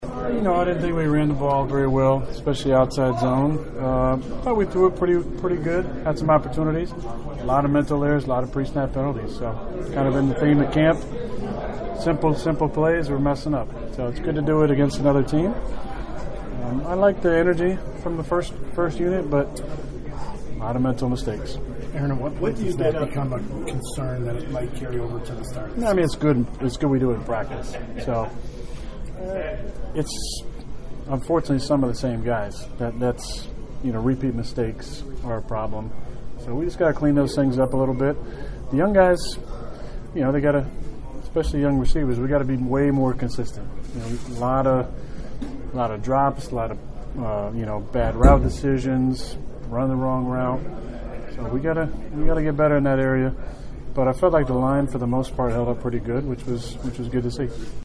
Inside the locker room, Aaron Rodgers, who got his most intense work of the year to date, offered a pretty frank assessment of how his unit fared against a very good New Orleans defense.